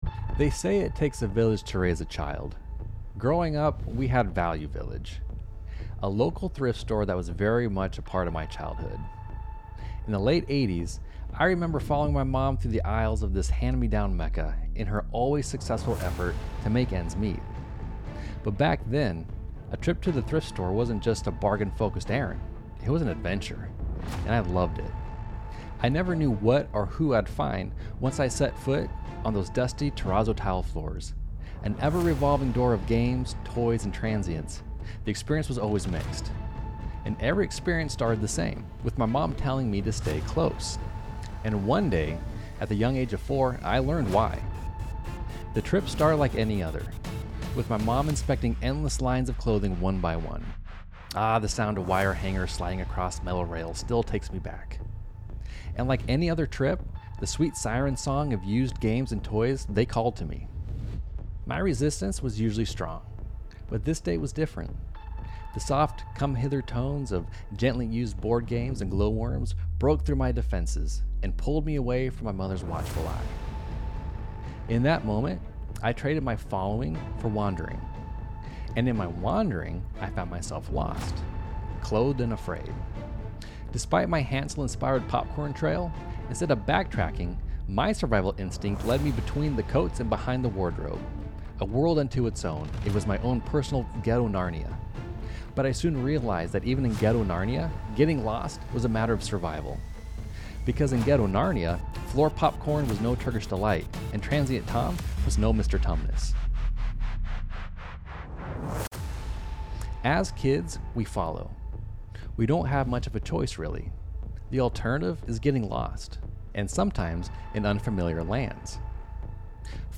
S1 E8 | "Follow" | Bible Study John 1:43-45